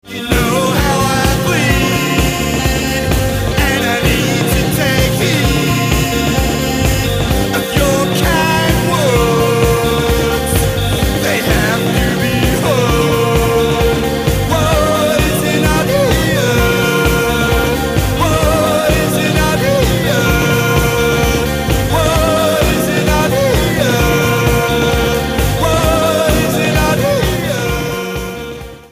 Third studio album